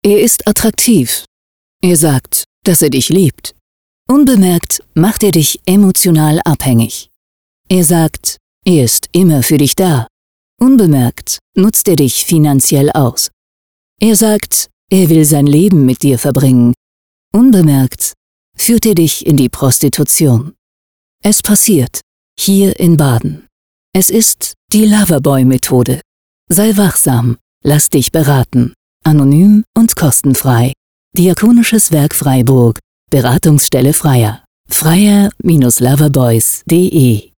gute-tat-mit-radio-und-plakat---diakonisches-werk-freiburg--freija---38sek--ohne-musik--ohne-abbinder-.mp3